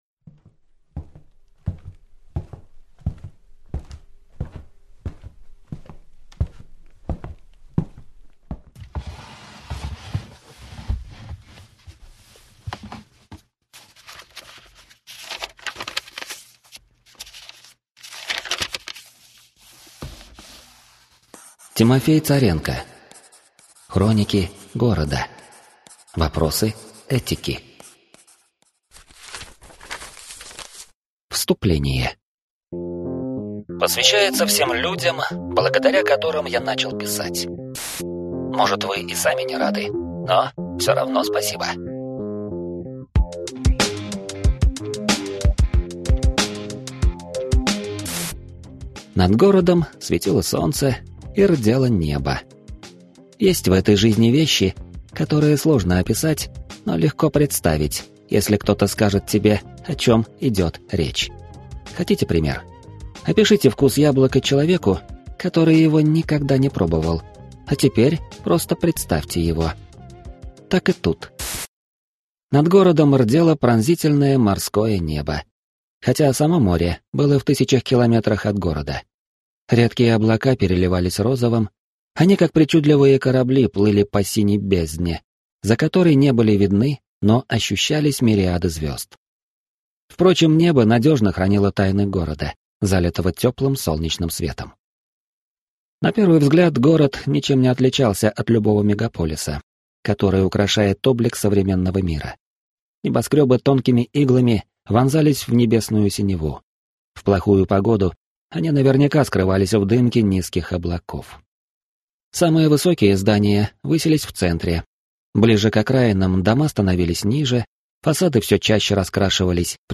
Аудиокнига Хроники города. Вопросы Этики | Библиотека аудиокниг